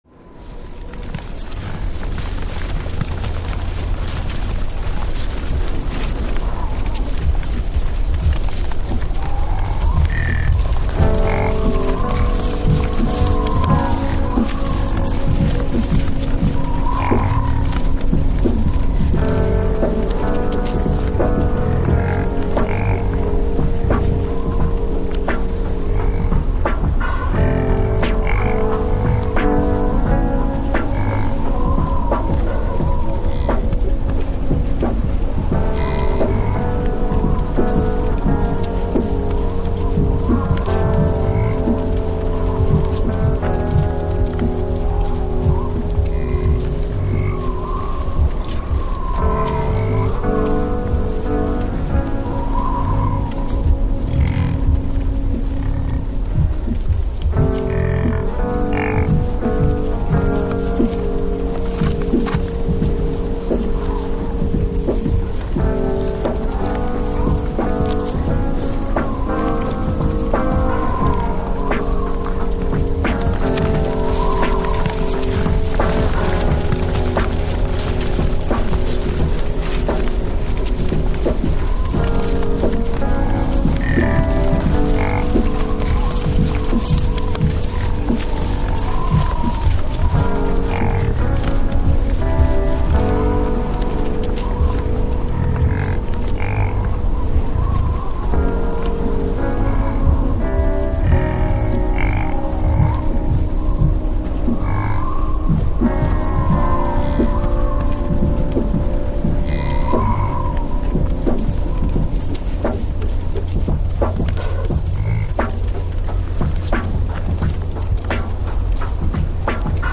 1. Environmental sounds related to the historic period
3. Composed musical sequences.